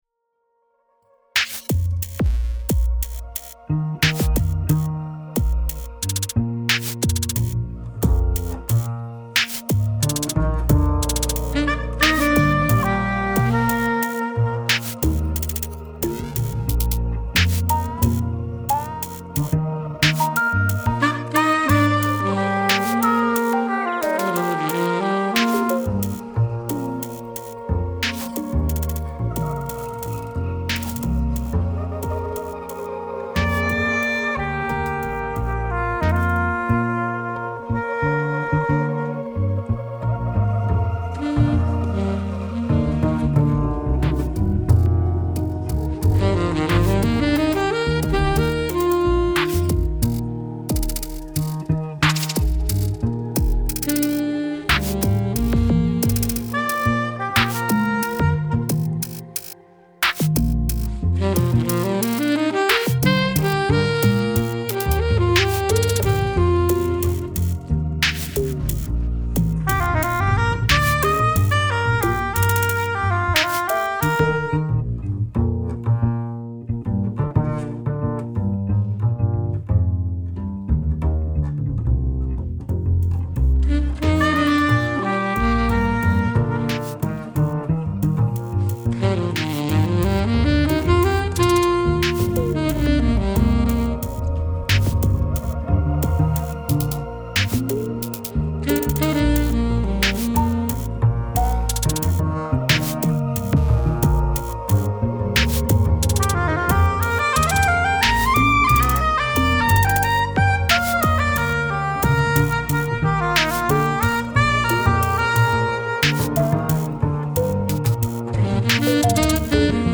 Klangvergleich Bässe: Kanal rechts: SLB300 direkt Kanal links: akustischer Bass mit EV RE20 aufgenommen Gebläse: Kanal rechts: Sopranino SN981 Kanal links: Alt A-WO20 Ähnliche Beiträge